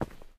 ROCK_2.ogg